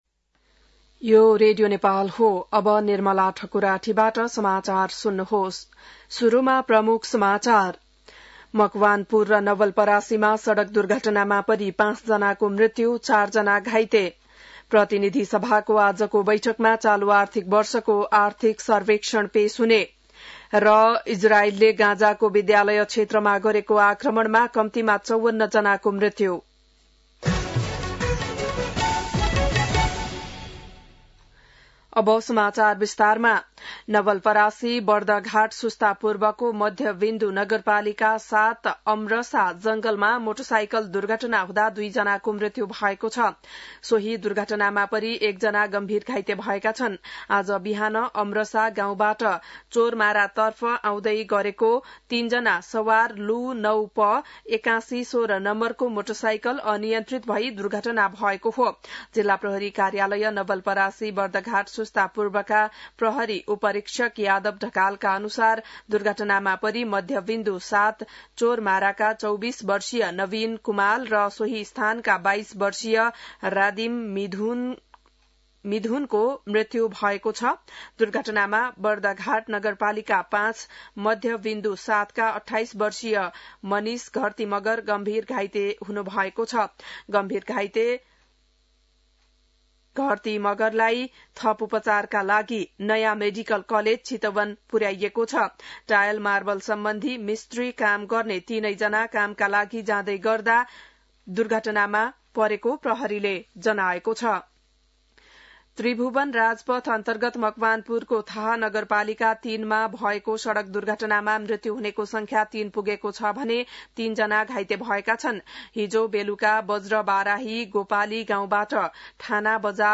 बिहान ९ बजेको नेपाली समाचार : १३ जेठ , २०८२